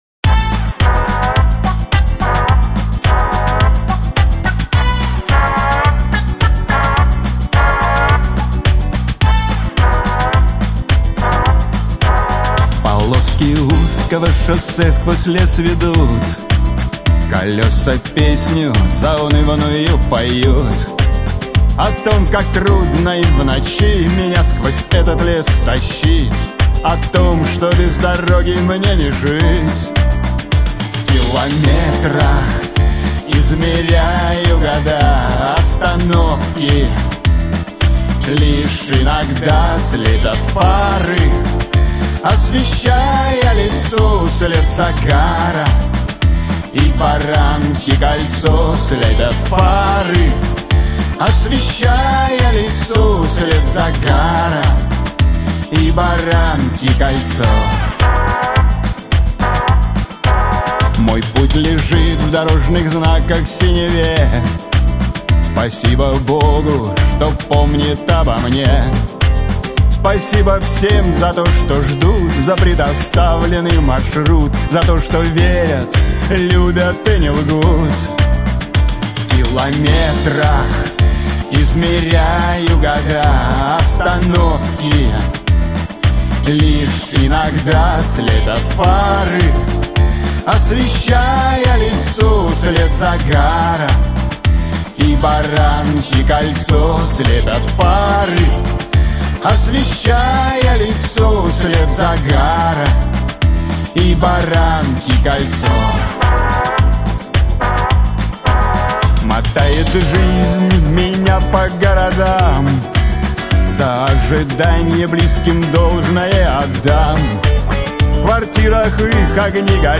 Главная » Музыка » Шансон